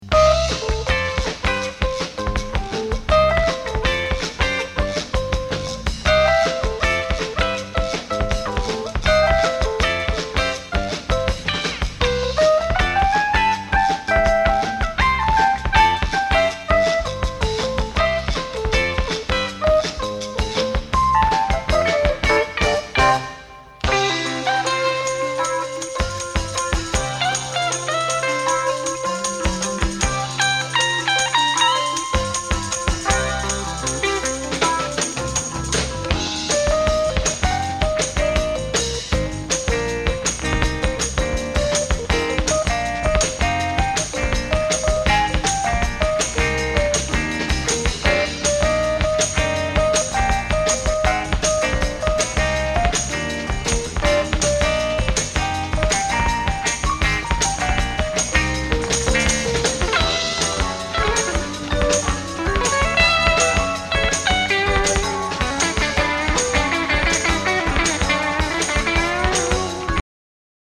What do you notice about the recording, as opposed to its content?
Recorded ‘live’